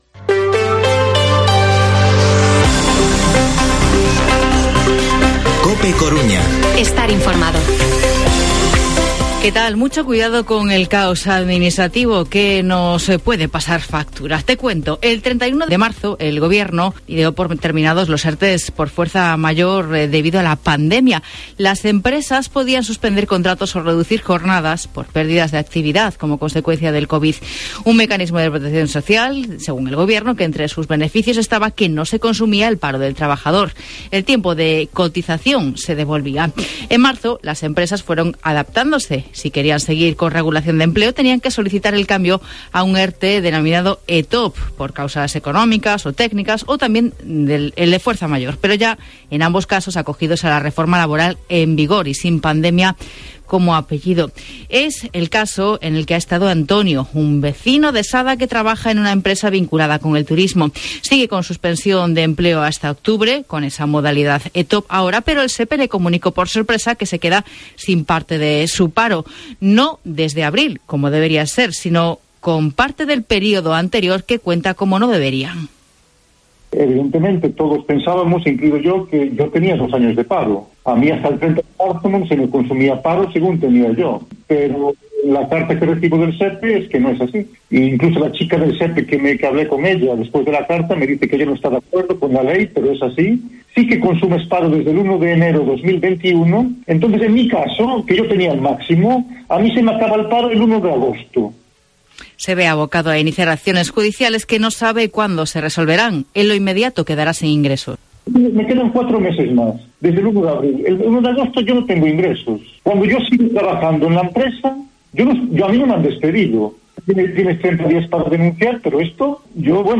Informativo Mediodía COPE Coruña lunes, 2 de mayo de 2022 14:20-14:30